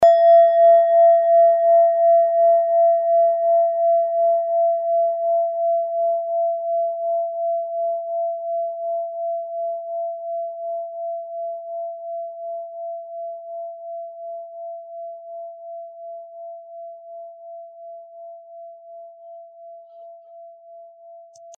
Klangschale Nepal Nr.3
Klangschale-Gewicht: 1120g
Klangschale-Durchmesser: 15,1cm
Die Frequenz des Jupiters liegt bei 183,58 Hz und dessen tieferen und höheren Oktaven. In unserer Tonleiter ist das in der Nähe vom "Fis".
klangschale-nepal-3.mp3